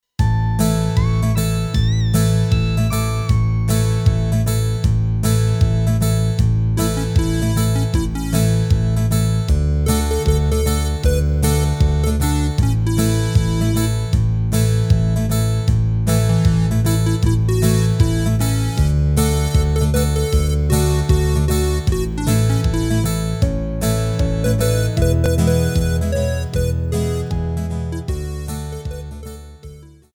Rubrika: Pop, rock, beat
Karaoke